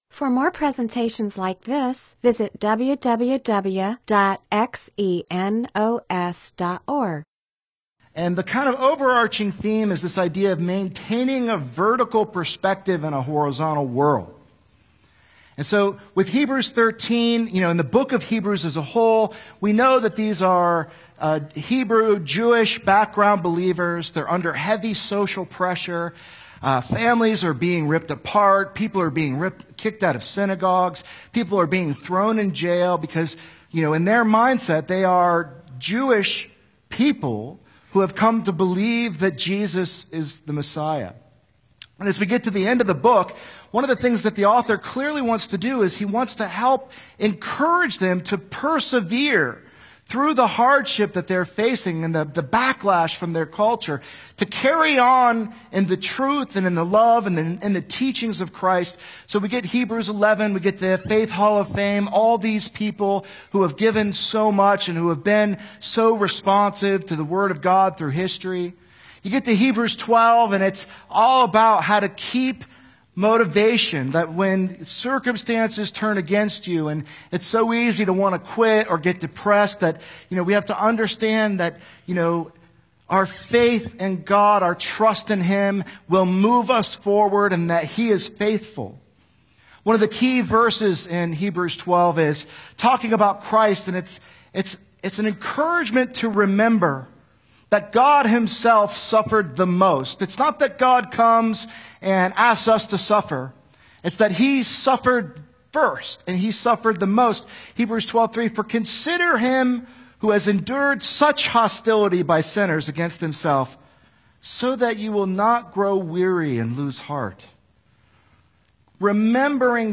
MP4/M4A audio recording of a Bible teaching/sermon/presentation about Hebrews 13:1-2; Hebrews 12:26-29.